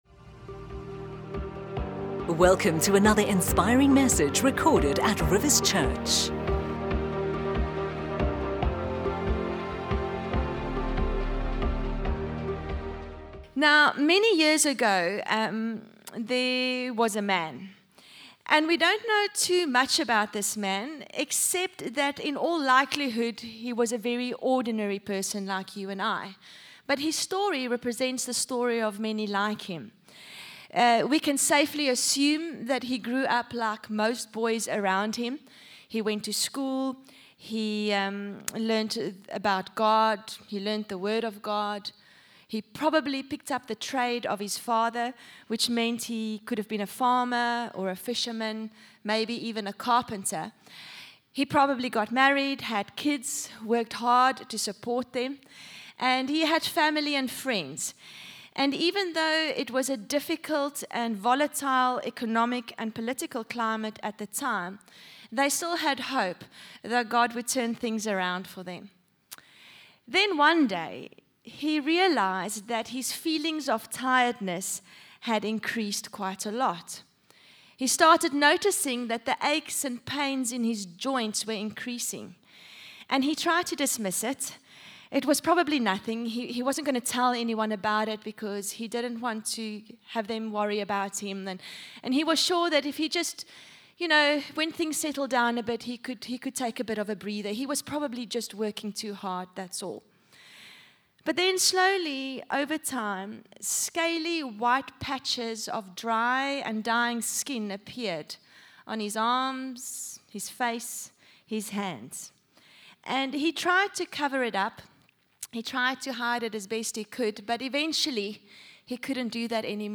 You can download our weekend messages for free!